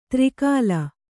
♪ tri kāla